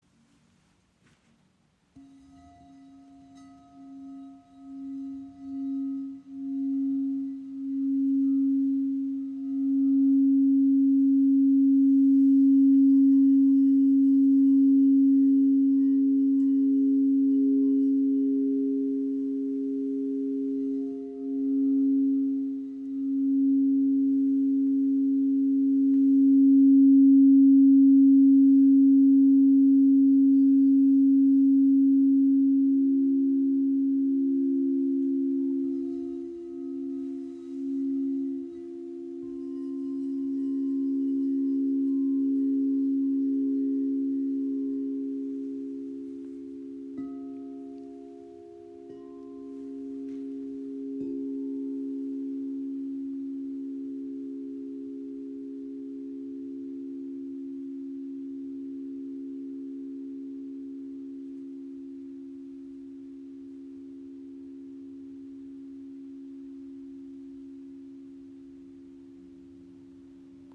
Klangbeispiel
Ihr klarer, schwebender Ton entsteht aus hochreinem Quarzglas und berührt jede Zelle im Körper. Der Akkord C# – F – G# wirkt dabei besonders lichtvoll und zentrierend – ideal für Klangtherapie, Meditation und energetische Arbeit.
Reine, sphärische Klänge aus Quarzglas – tief berührend und energetisierend
Weiterlesen Klangbeispiel Kristallklangschalen C# – F – G# in 432 Hz (Handyaufnahme)